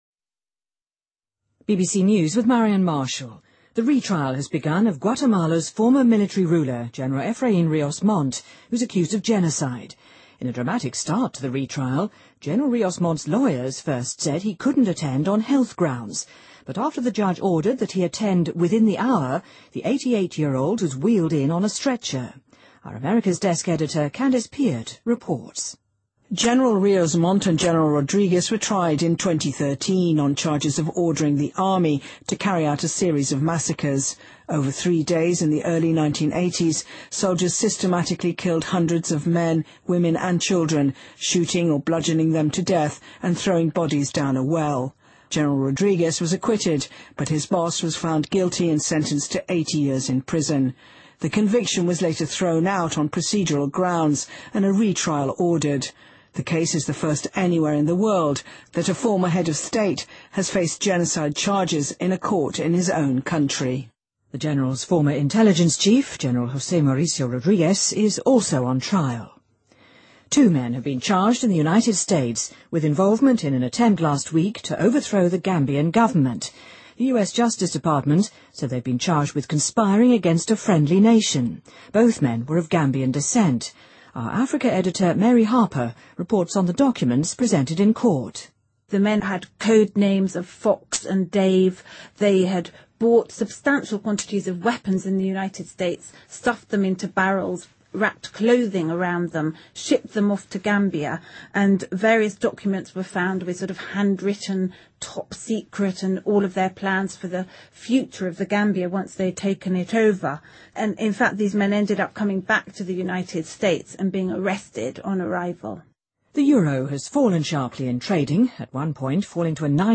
BBC news,以色列扣压巴勒斯坦数亿税款 称将继续报复